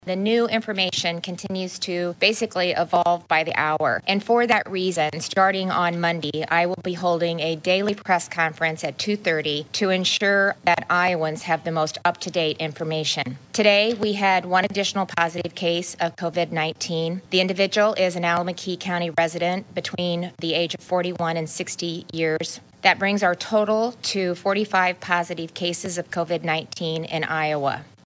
Gov. Kim Reynolds hosted another press conference this (Friday) afternoon and announced one additional case of COVID-19 had been identified in Iowa.
Friday-Presser-1.mp3